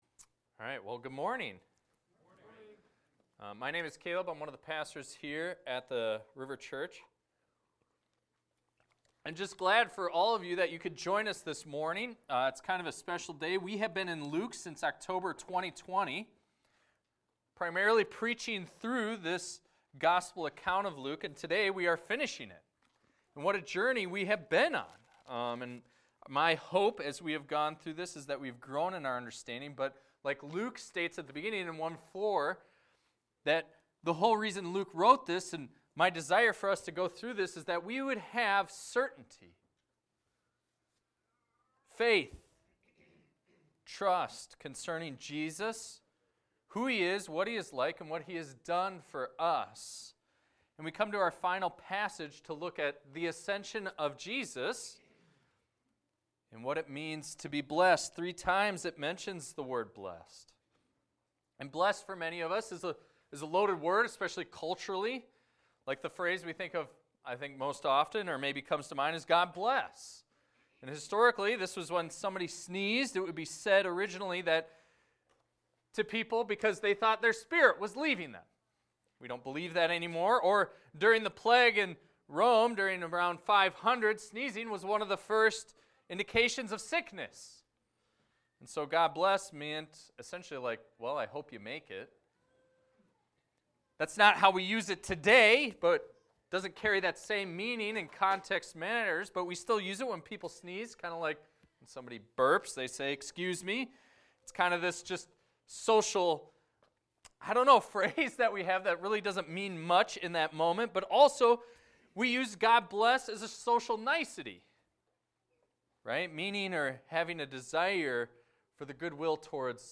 This is a recording of a sermon titled, "The King Ascends!."